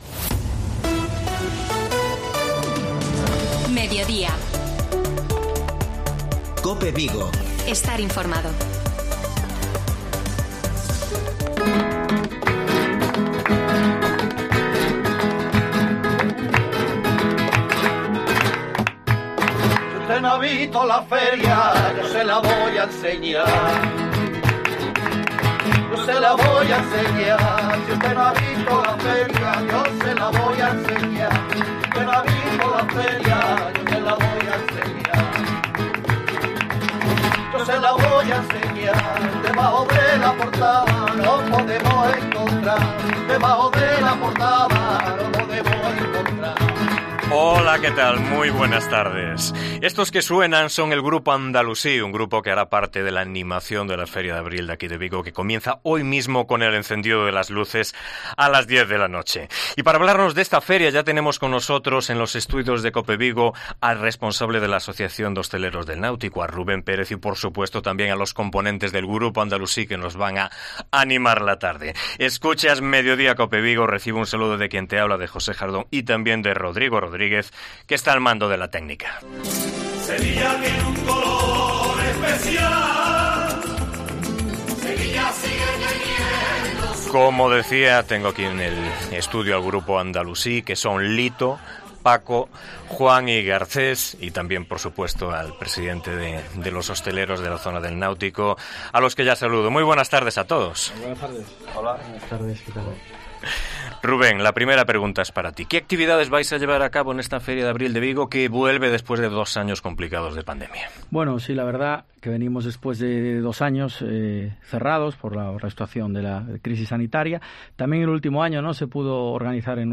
ENTREVISTA
El grupo Andalusí nos interpreta en directo varios temas que tocarán en la Feria de Abril de Vigo.